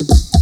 DRUMFILL15-R.wav